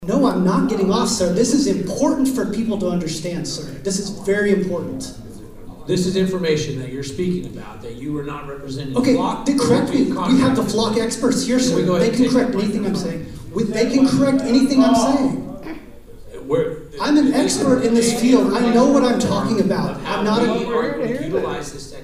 More than 100 people attended a two-hour-long forum at Oklahoma Wesleyan University Thursday evening on the Bartlesville Police Department's use of Flock Automated License Plate Reader cameras.
The panel also fielded several in-person questions during the forum, causing some friction, especially with one attendee who was warned that he would be asked to leave if
Interruption at forum 1-16.mp3